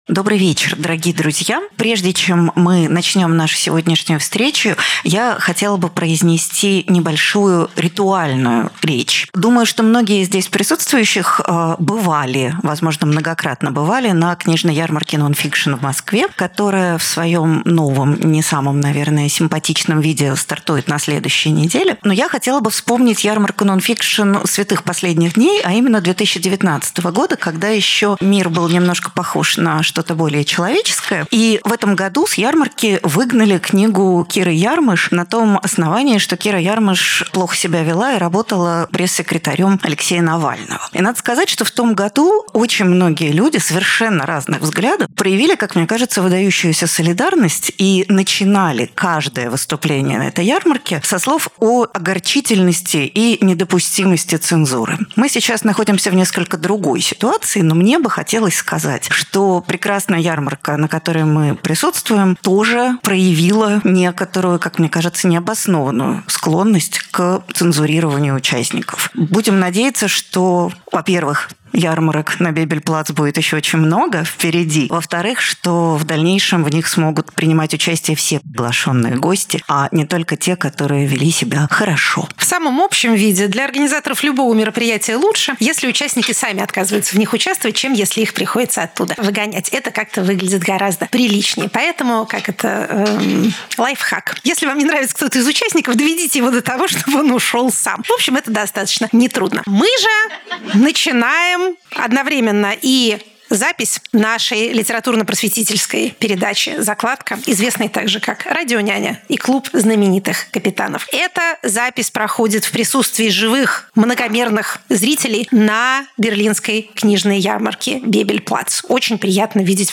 Екатерина Шульманполитолог
Галина Юзефовичлитературный критик